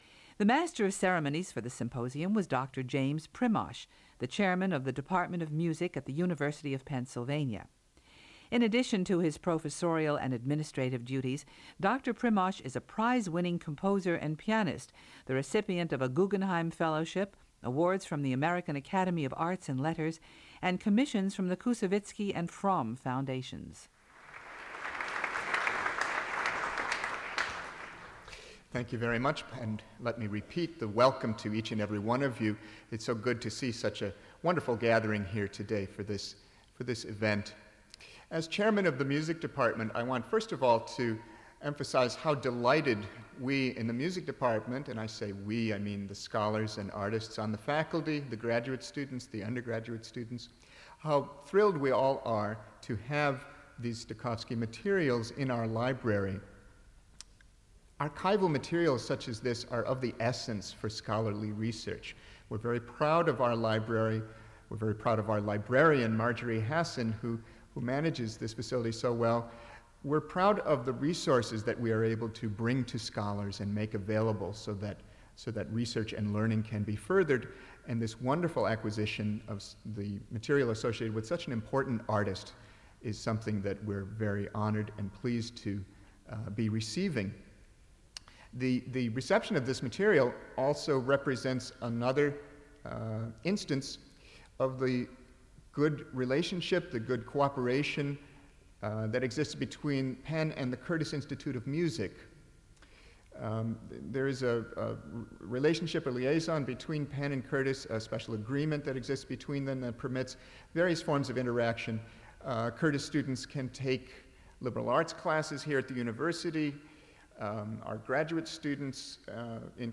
A presentation made as a part of the symposium "Leopold Stokowski: Making Music Matter," University of Pennsylvania, 15 April 1998.
Transferred from cassette tape.